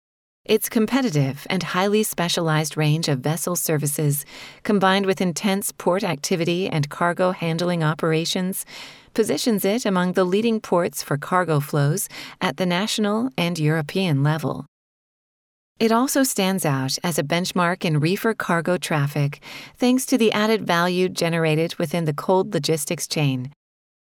Voiceovers American English  female voice overs. Group A